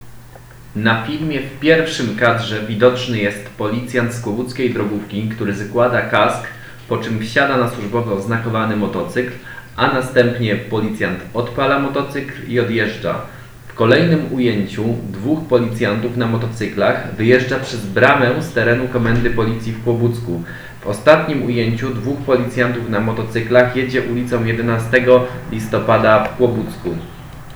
Nagranie audio Aduiodeskrypcja_-policyjne_motocykle_29_03_2022.m4a